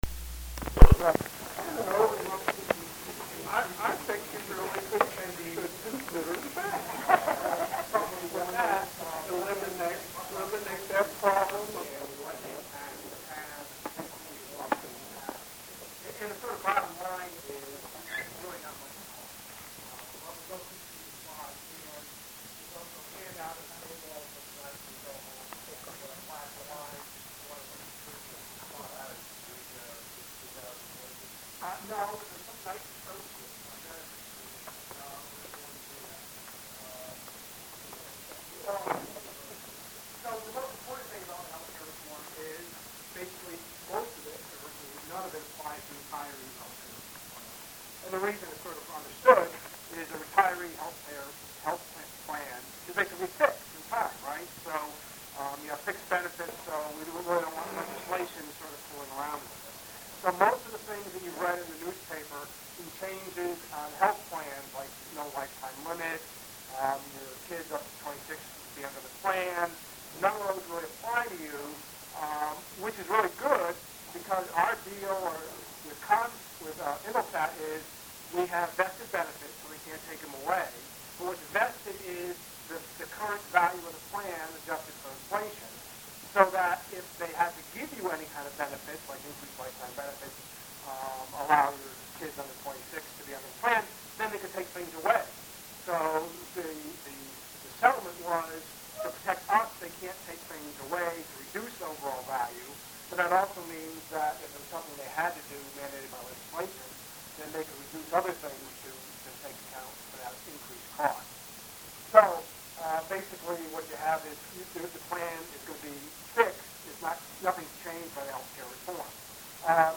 There were about 45 members in attendance.
Presentation